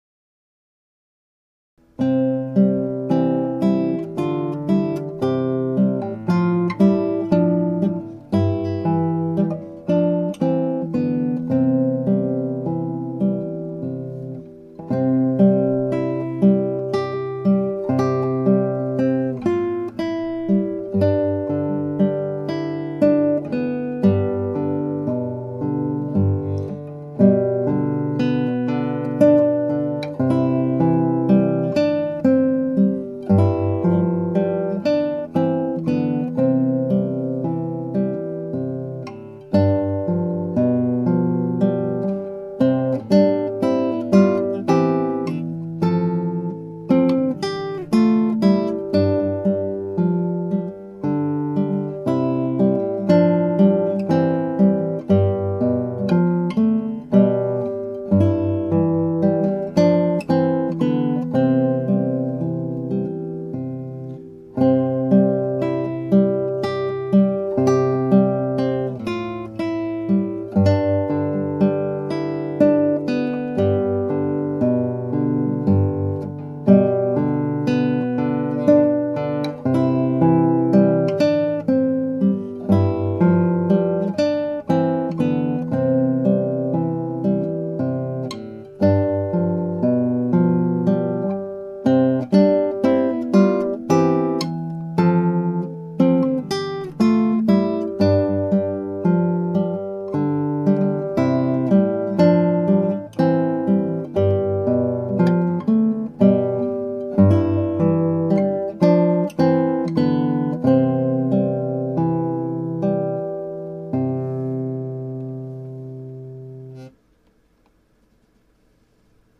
Guitar amatuer play